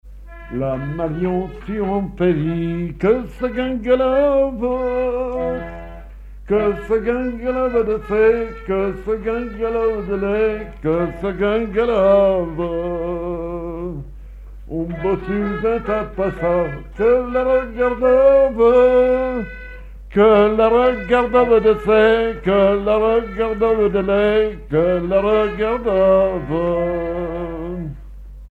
Peillonnex
Pièce musicale inédite